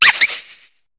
Cri de Chlorobule dans Pokémon HOME.